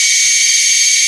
rr3-assets/files/.depot/audio/sfx/transmission_whine/kers_onhigh.wav
kers_onhigh.wav